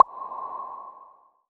Airy Bubble Pop Notification.wav